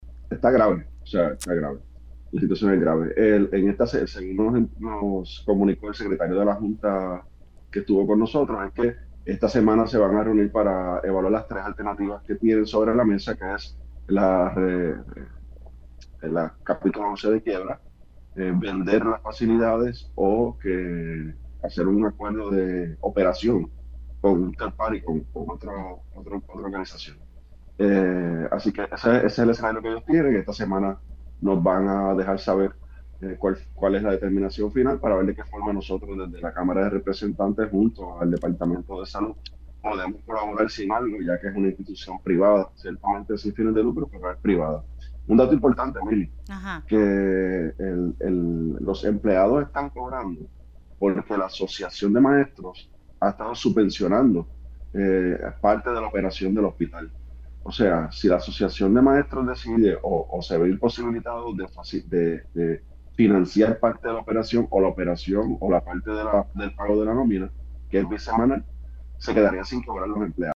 No hay forma de sostenerlo“, detalló el legislador en Pega’os en la Mañana.